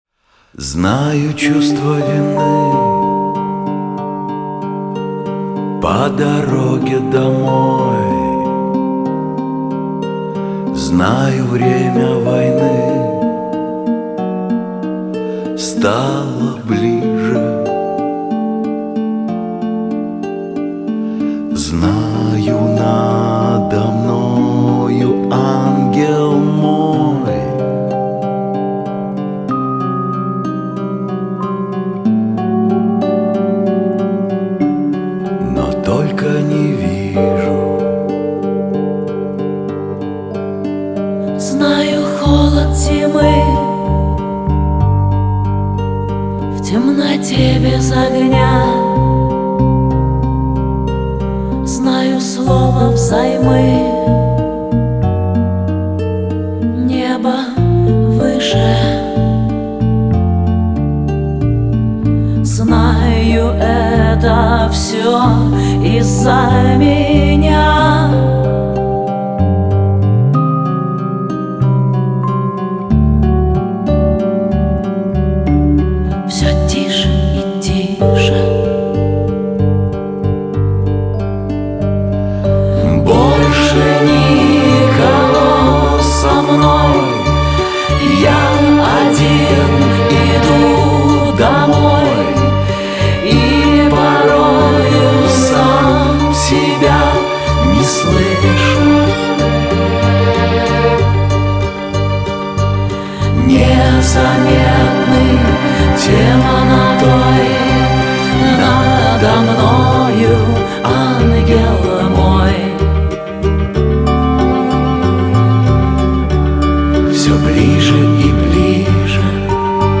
Главная » Файлы » Rock 2016